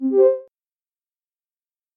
Звук доступ разрешен